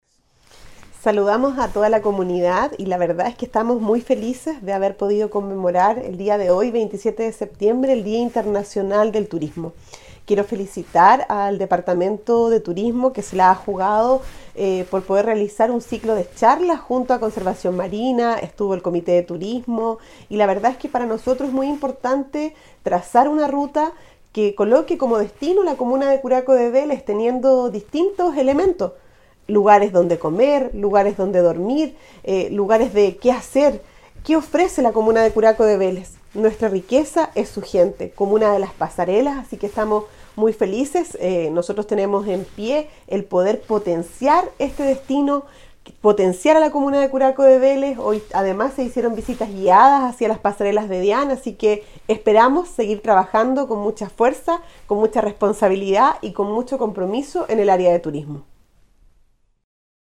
En la oportunidad, la edil Javiera Yáñez señaló: